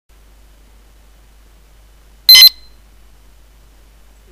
○予鈴